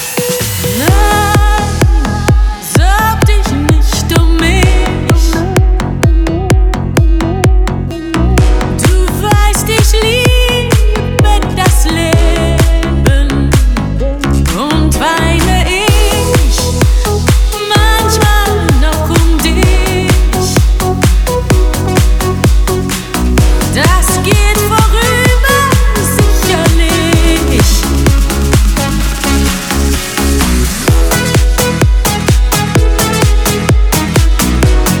Жанр: Поп
# German Pop